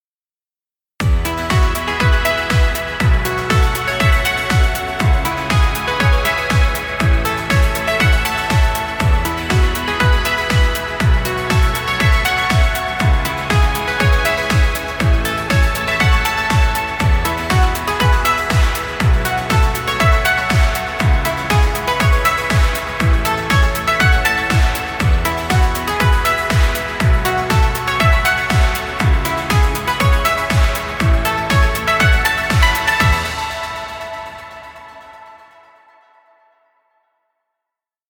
Dance music for video.